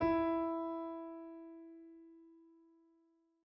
piano-sounds-dev
e3.mp3